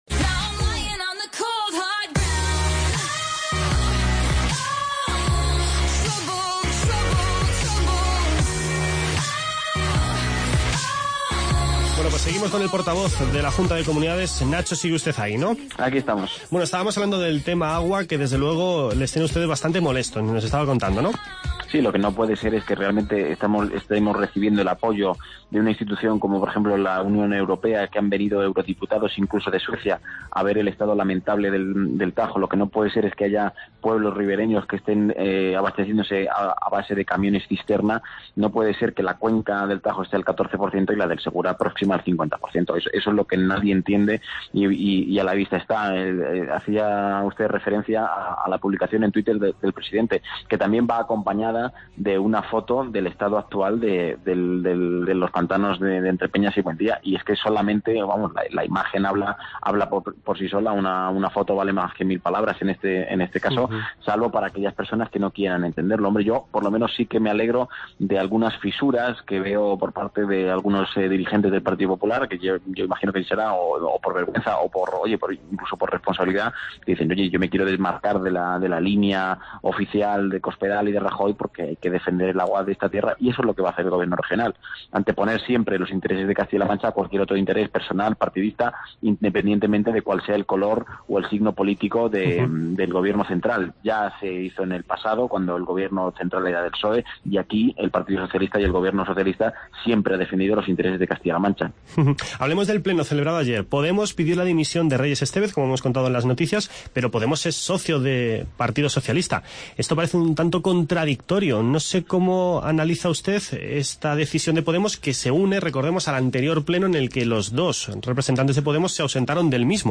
Escuche las entrevistas con Nacho Hernando, portavoz del Gobierno, y con Álvaro Gutiérrez, presidente de la Diputación Provincial de Toledo.